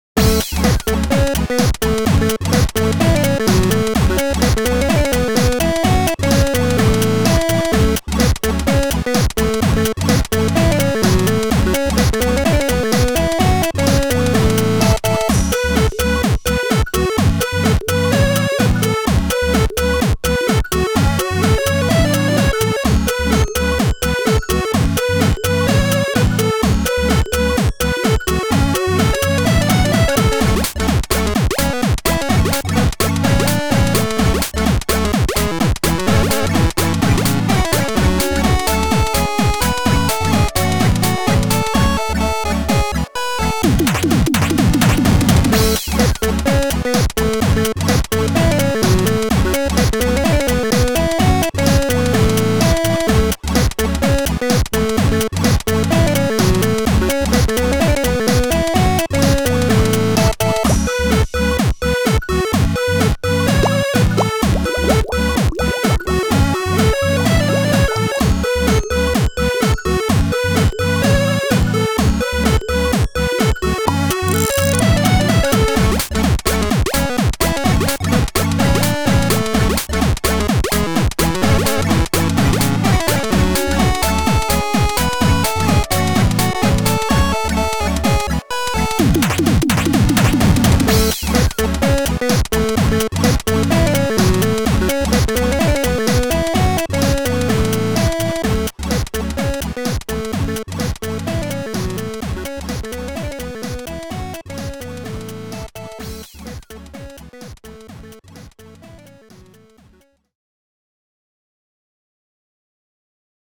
あかるい かわいい